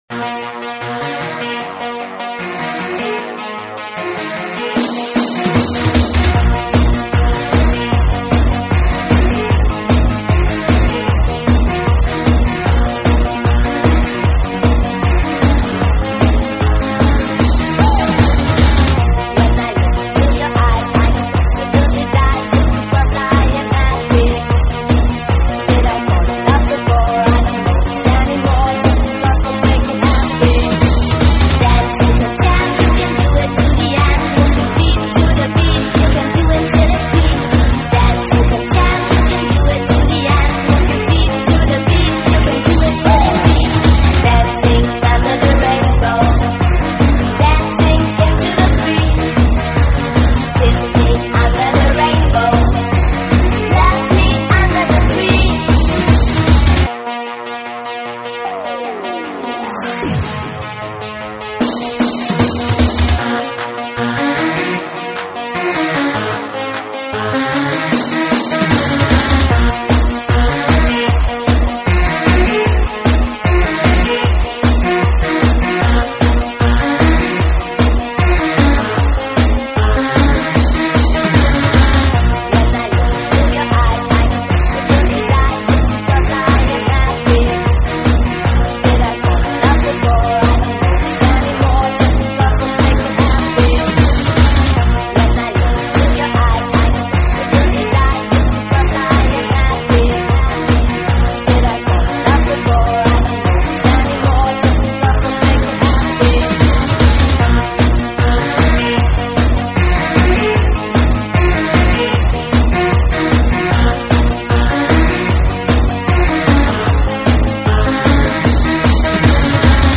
danse sag
Lydkvaliteten er ikke så god, da programmet der skulle omdøbe den til Mp3 er dårligt.